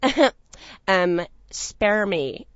gutterball-3/Gutterball 3/Commentators/Poogie/spare_me.wav at patch-1
spare_me.wav